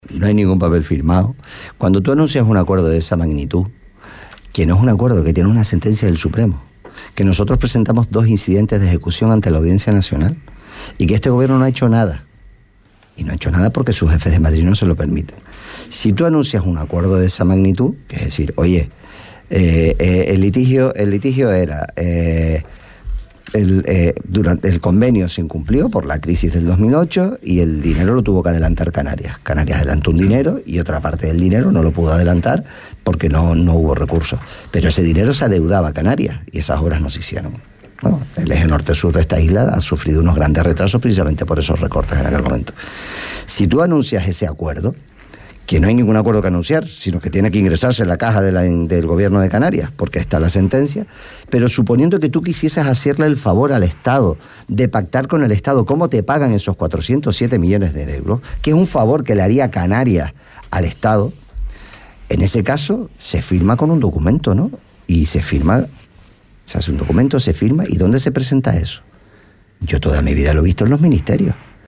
Fernando Clavijo en los estudios de Radio Sintonía
Fernando Clavijo, secretario general de Coalición Canaria – PNC, visitaba esta mañana los estudios de Radio Sintonía. Entre los temas abordados, se trataba el conflicto de las relaciones con Marruecos y la línea con Tarfaya.